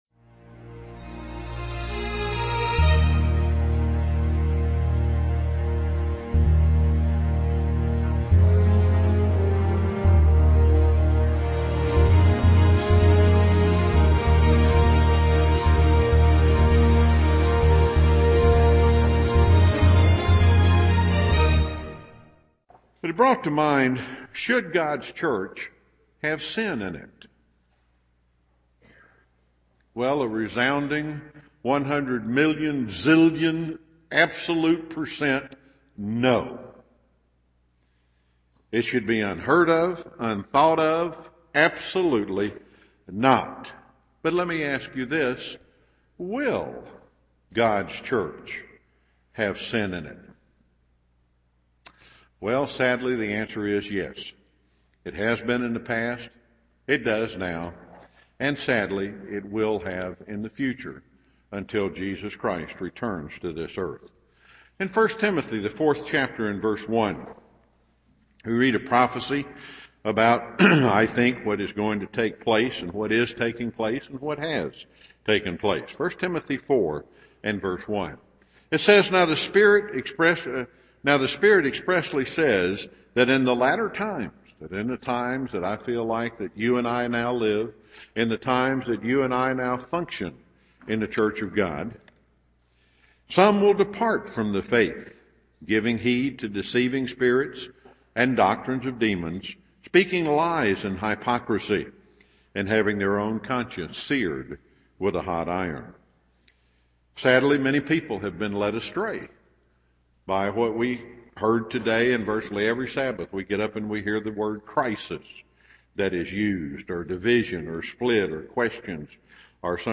Given in Chattanooga, TN
Satan is the great deceiver and wants to destroy us. 1 Timothy 4:1 UCG Sermon Studying the bible?